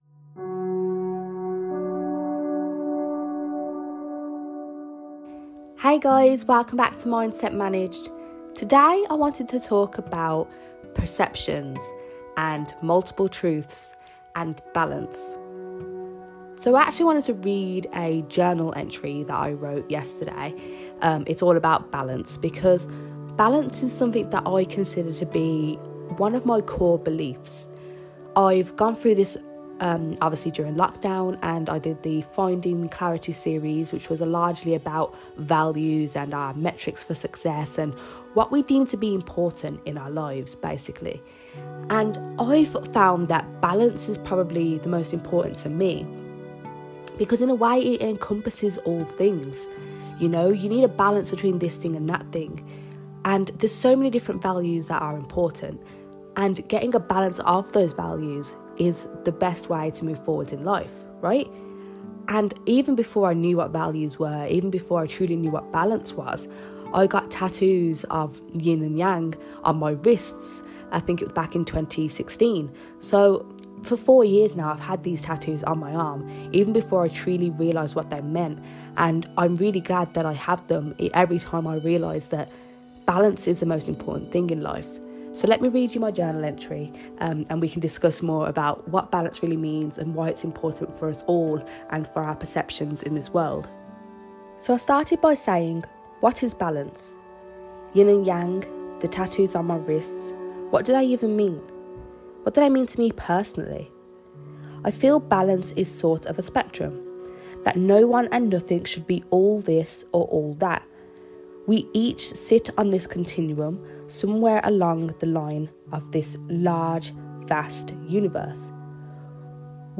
Today I’m doing a rambly philosophical chat inspired by the idea of balance, spectrums, and not being all this or all that. How we are all on a continuum, shifting up and down, never fixed…unless we decide to be.